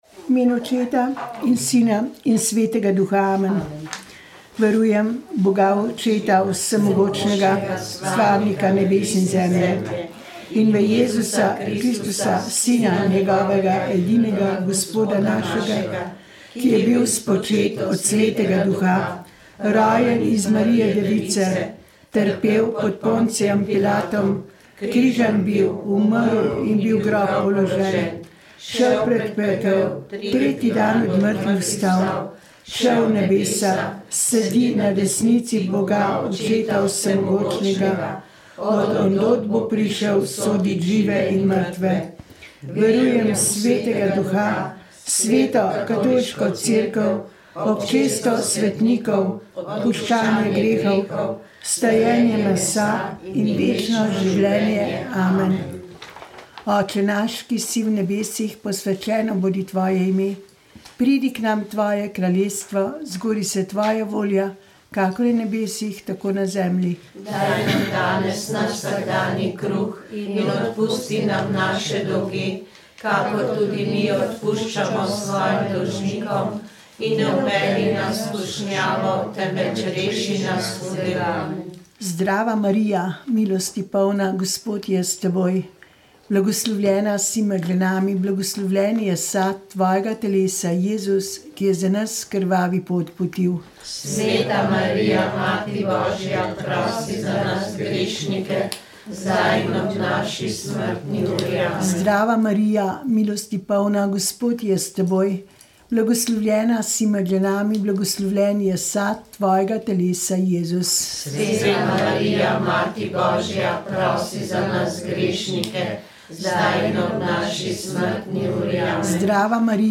Rožni venec
Molili so člani Karitas iz župnije Ljubljana - Vič.